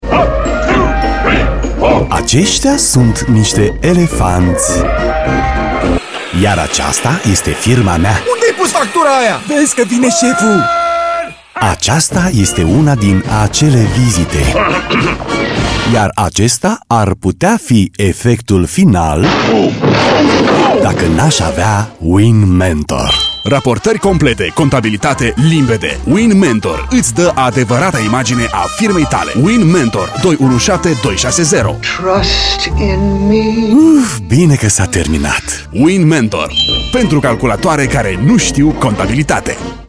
SPOTURI RADIO